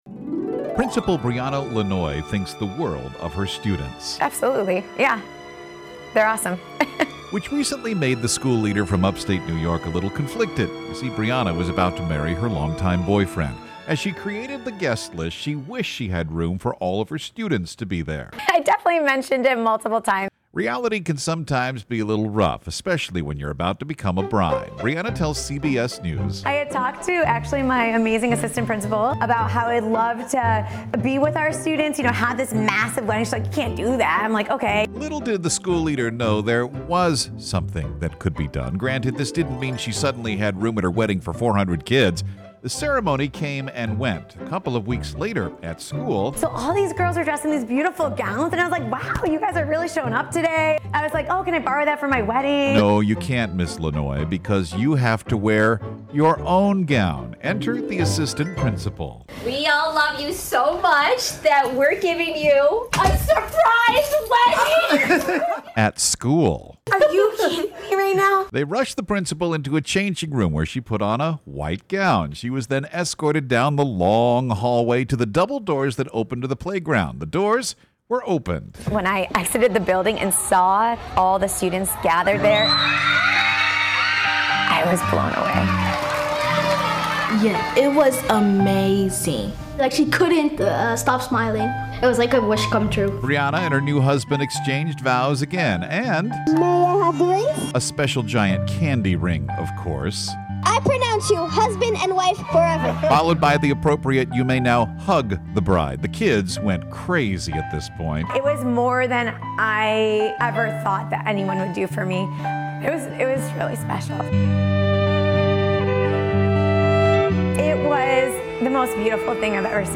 Take a listen to the entire story as aired on Northwest Newsradio to hear the kids react to this special day at school.